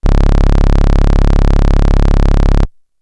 808s
C#_07_Bass_02_SP.wav